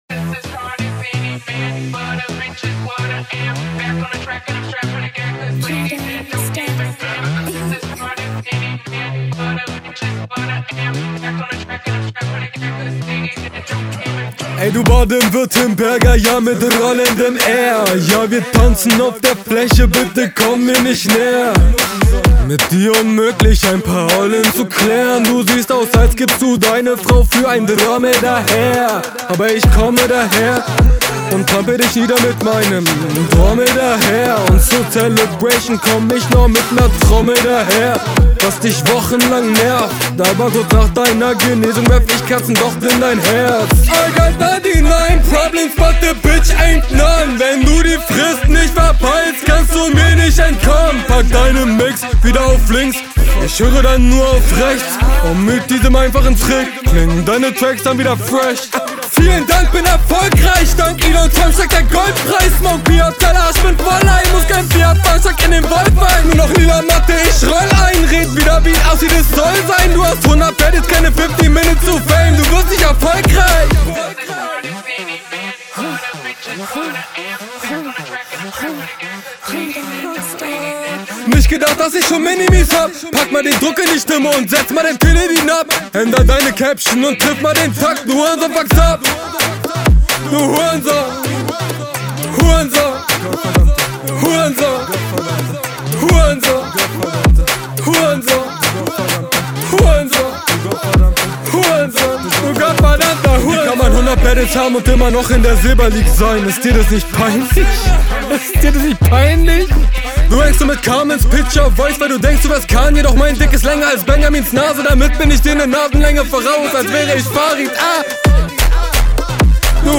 Starke Energie und hat paar gute highlight bars.